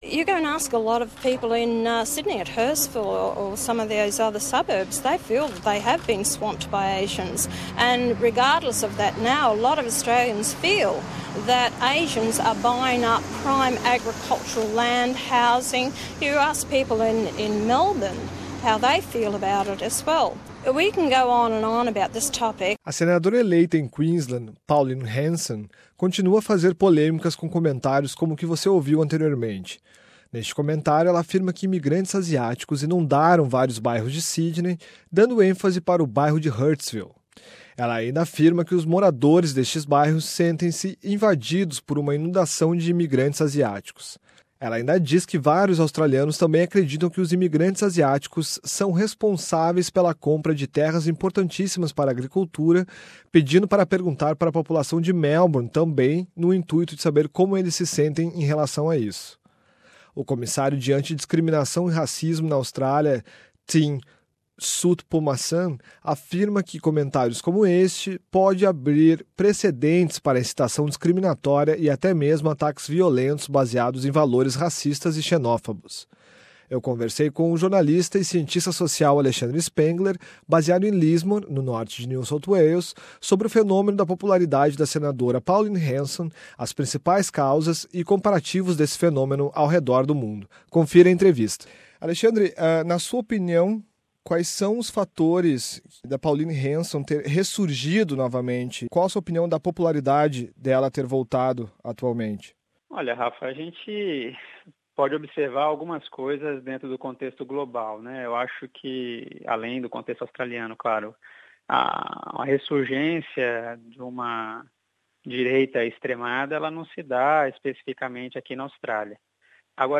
Com a vitoria da senadora Pauline Hanson e a possibilidade de mais um assento do partido conservador One Nation no senado, a Australia apresenta uma mudanca representativa no cenario politico, como vem acontecendo em outros lugares do mundo. Nos conversamos com o cientista social e jornalista